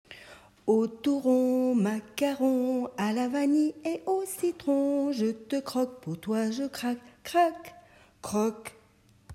Merci de votre indulgence pour les enregistrements improvisés !
Chanson :
O-chanté.m4a